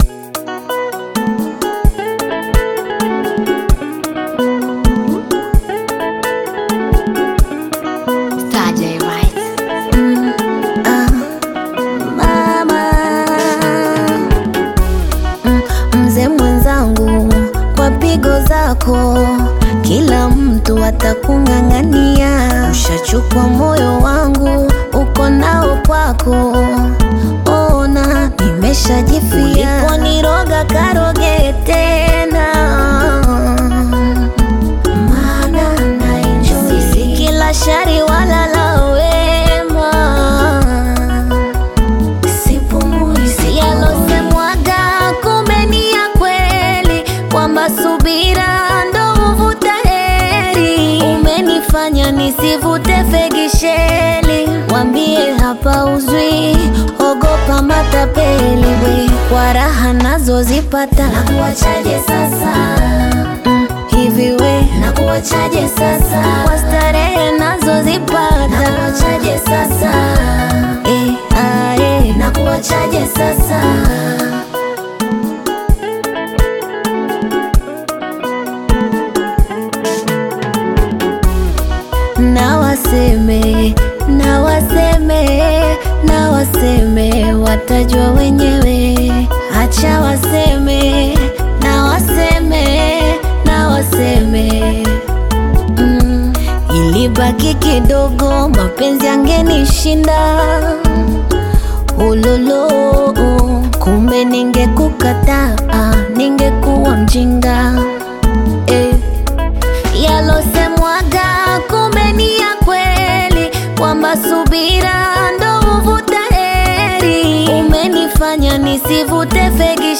Tanzanian Bongo Flava
This catchy new song